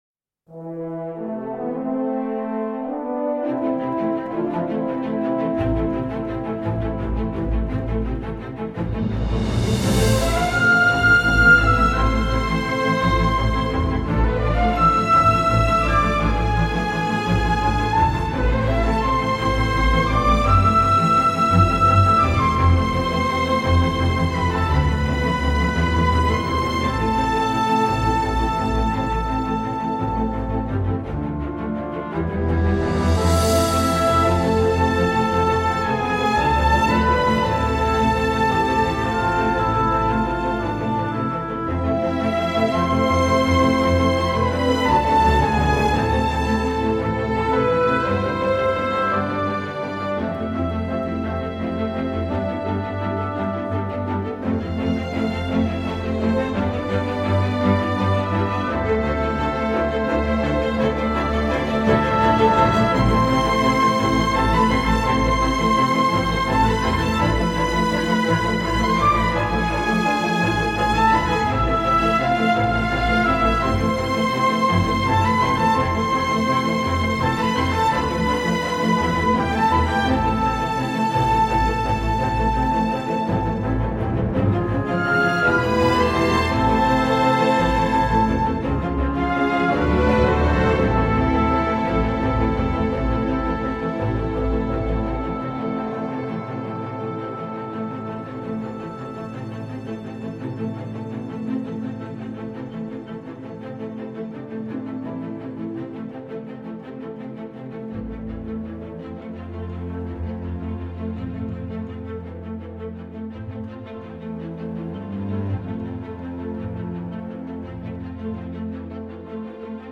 Une charmante œuvre orchestrale qui fait chaud au cœur.